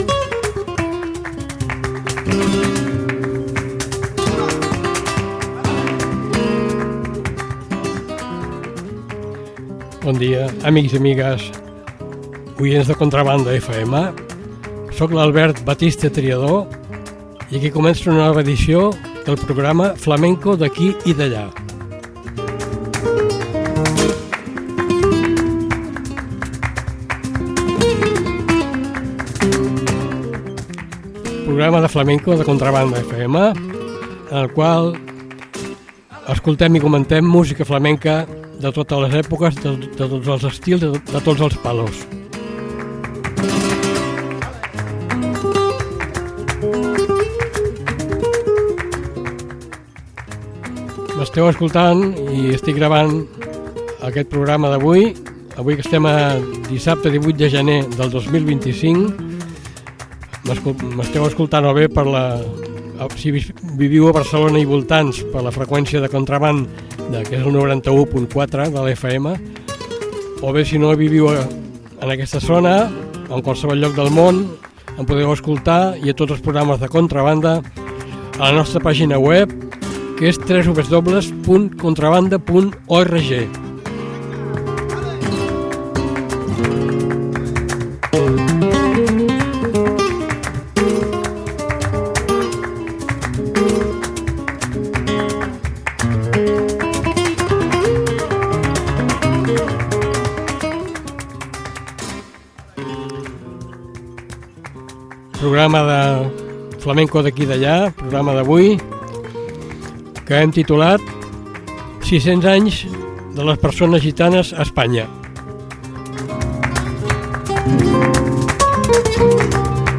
La situació actual del col.lectiu. Quatre cantaores/as gitanos/es.